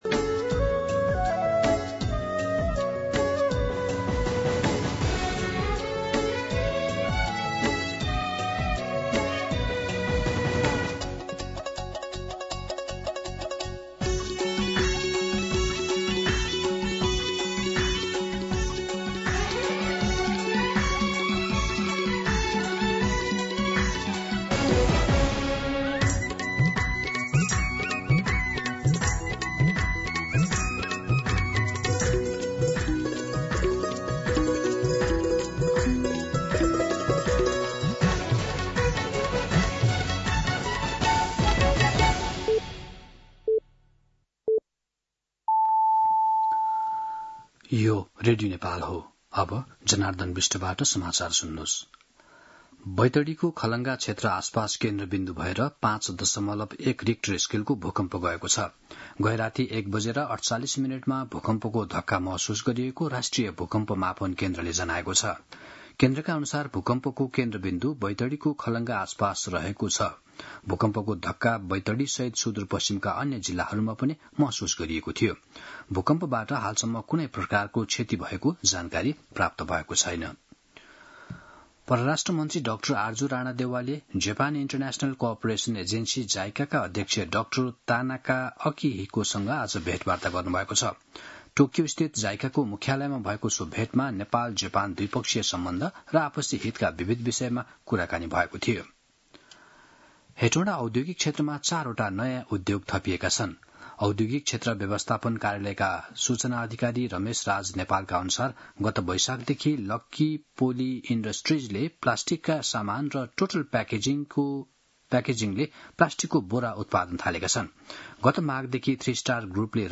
मध्यान्ह १२ बजेको नेपाली समाचार : ९ जेठ , २०८२